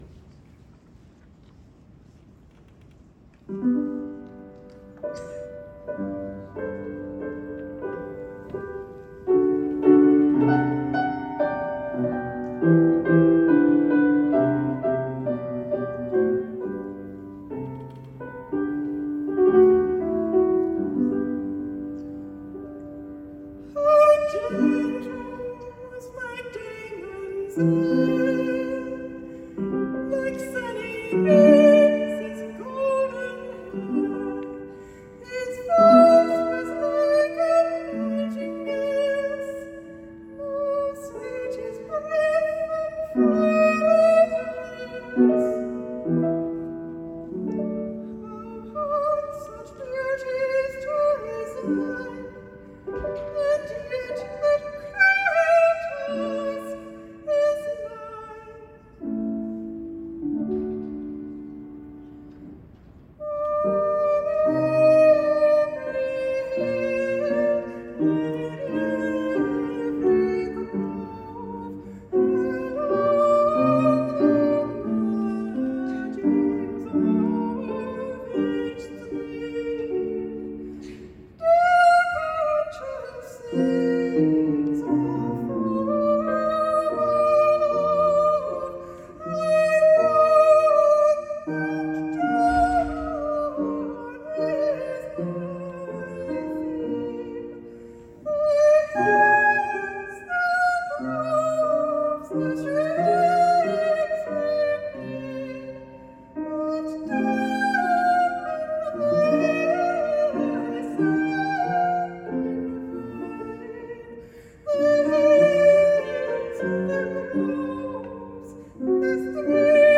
The three songs are all quite different:  the first, “How Gentle Was My Damon’s Air,” is a Baroque-style recitative and aria, the lament of a nymph who has lost her lover.
in Cleveland, Ohio